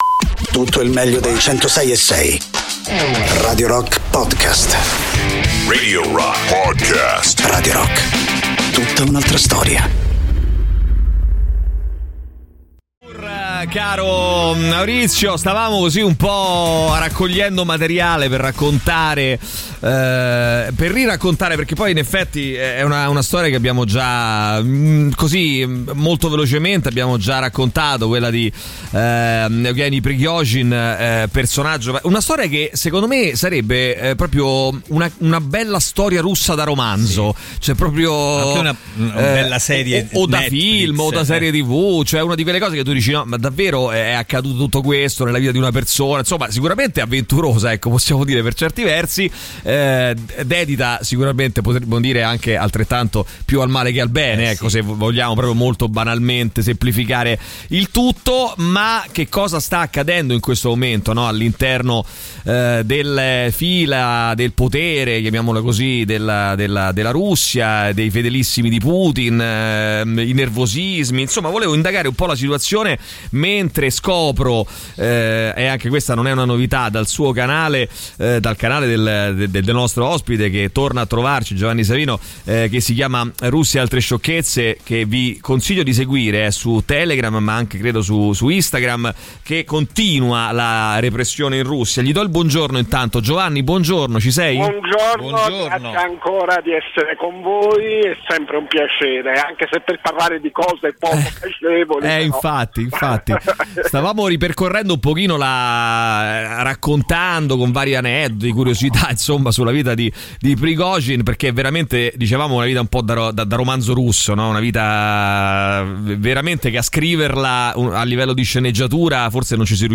Interviste
in collegamento telefonico